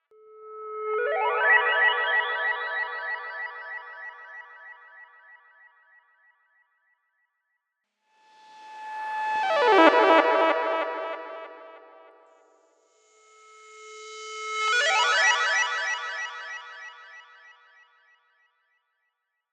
synthsparkle.wav